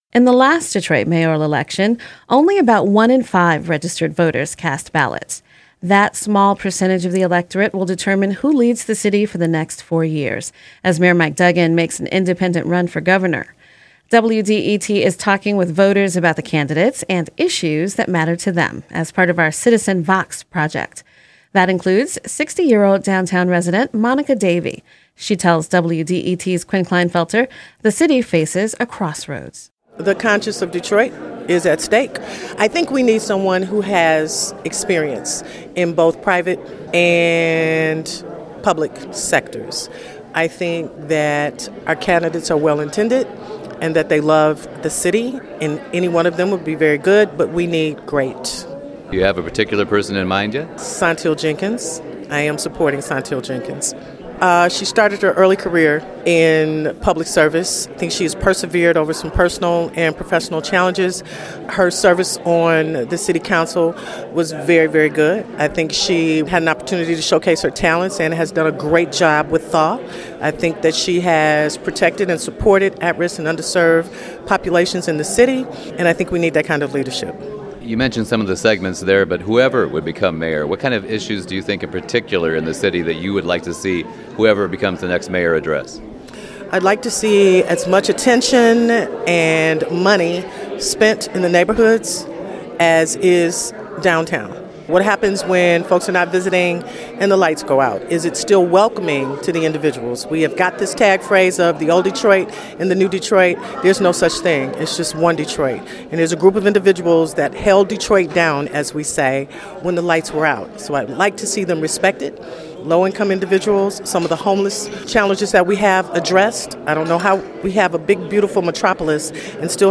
The following interview has been edited for clarity and length.